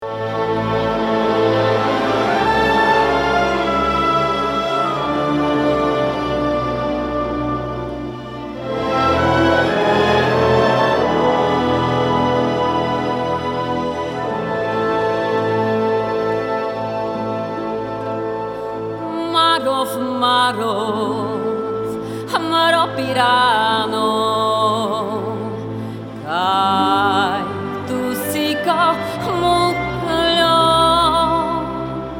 music: Traditional
key: A - Minor